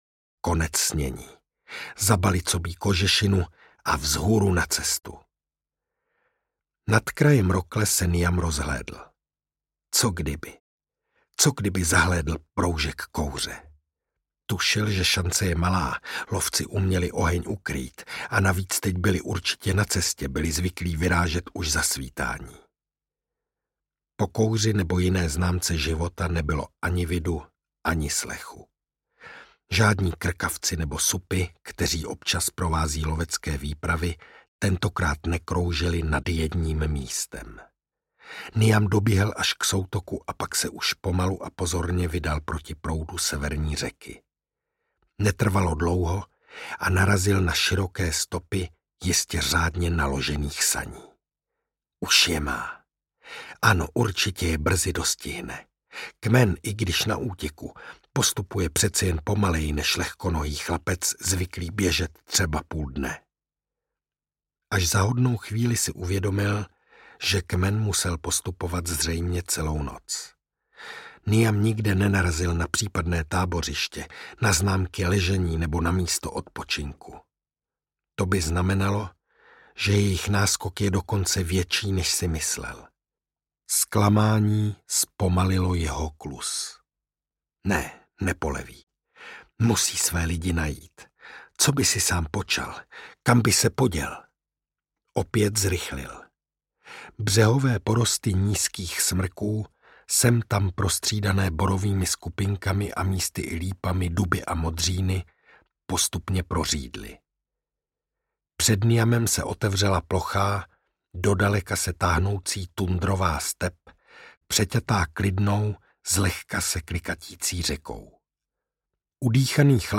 Ztracen v zemi mamutů audiokniha
Ukázka z knihy
Vyrobilo studio Soundguru.